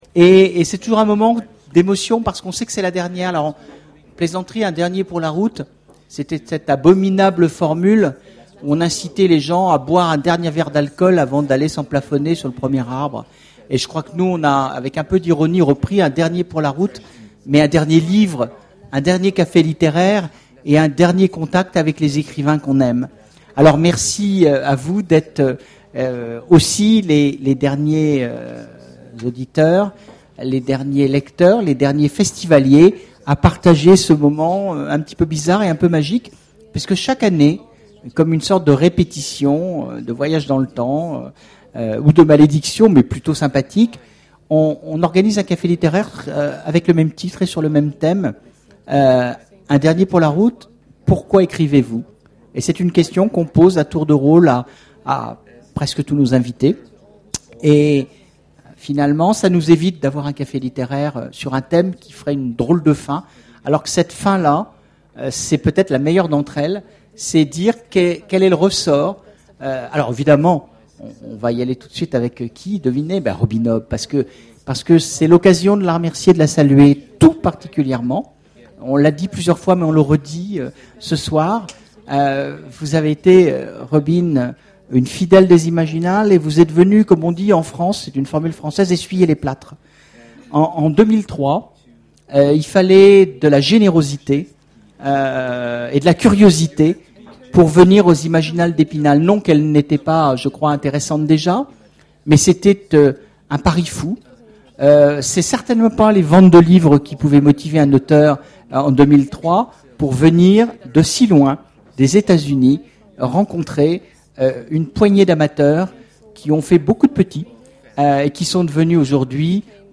Voici l'enregistrement de la conférence "Un dernier pour la route".